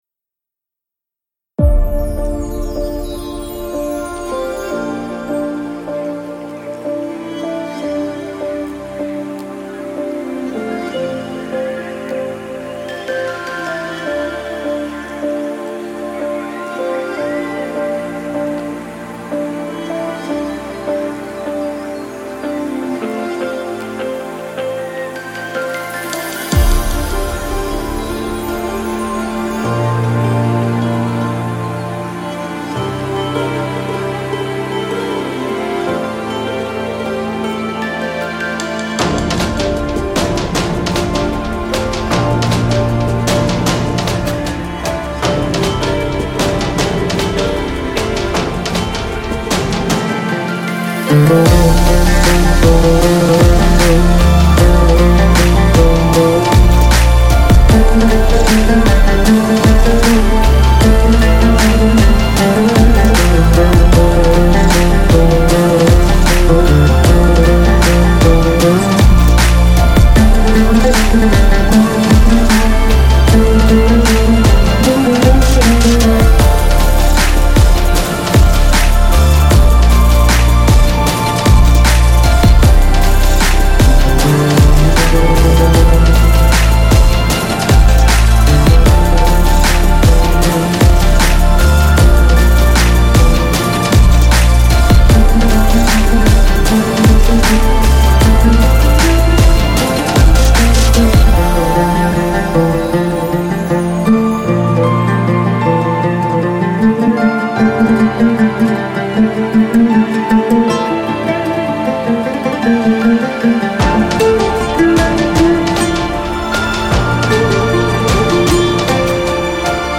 Audio Branding Elements
Music Theme – Epic Version